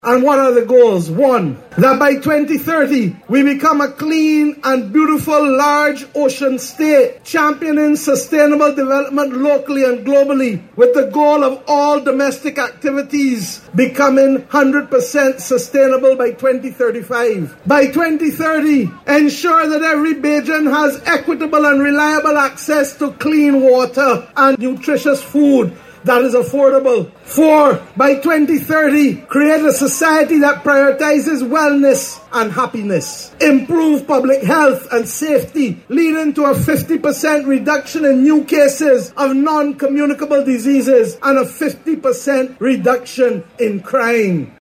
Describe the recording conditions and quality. The social partnership yesterday, signed the declaration of Mission Barbados document during Barbados Worker’s Union’s May Day Celebrations at the National Botanical Gardens.